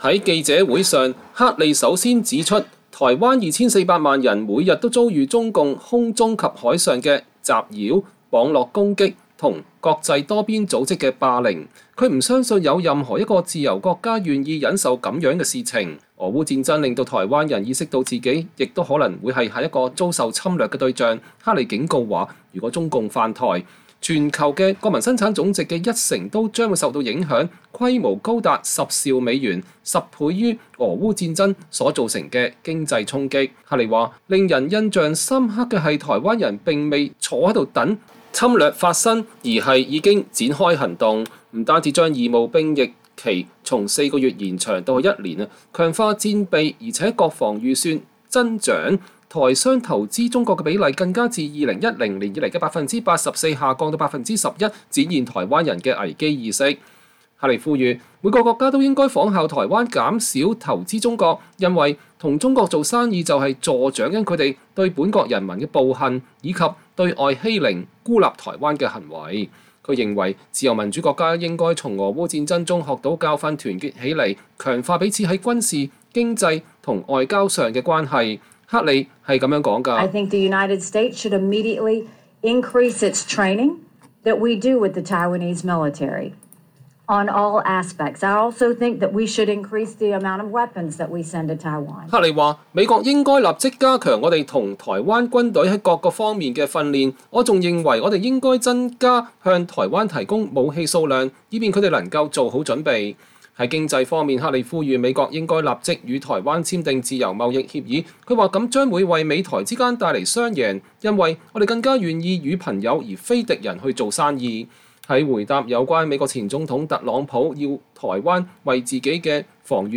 正在台灣訪問的美國前駐聯合國大使妮基·黑利(Nikki Haley)星期六召開記者會，直言她此行目的就是要向美國人展示為何需要關注台灣議題。黑利強調，美國不僅應立刻協助台灣強化軍備和訓練，也應加速簽署美台自貿協定(FTA)，並支持台灣至少以觀察員身分參與聯合國。